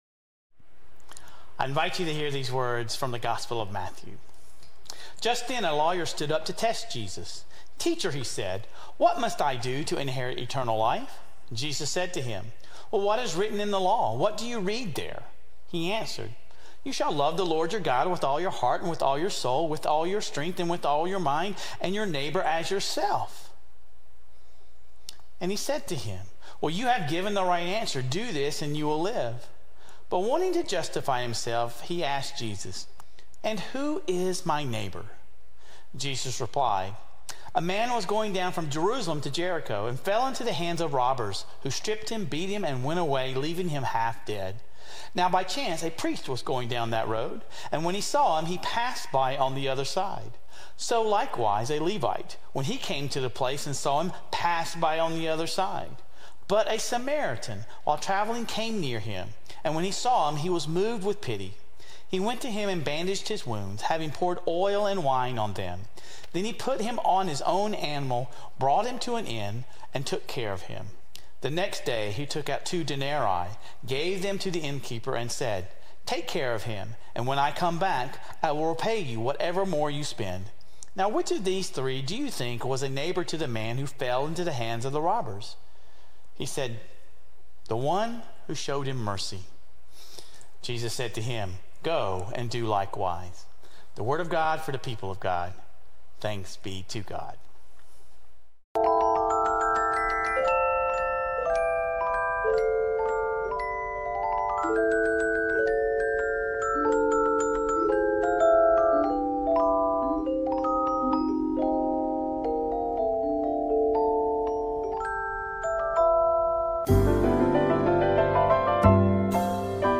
Sermon Reflections: What is the difference between being a neighbor and merely a person who lives nearby?